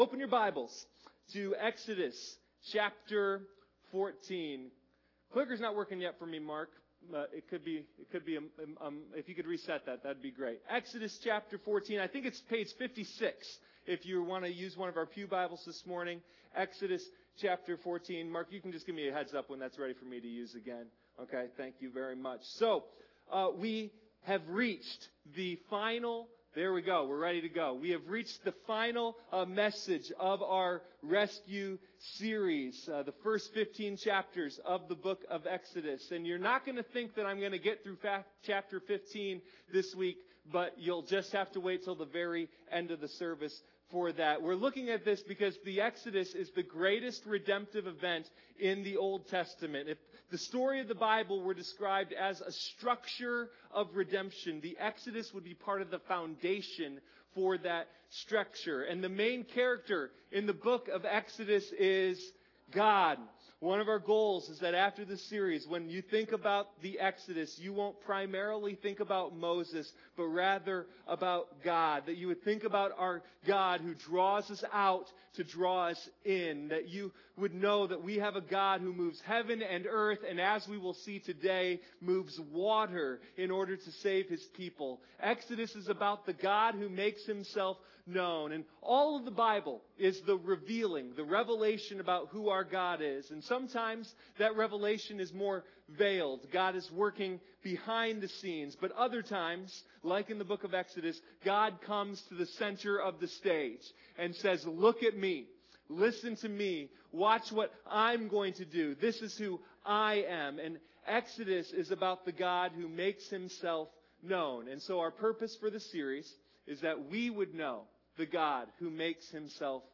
Sunday Morning Rescue: A Study of the Exodus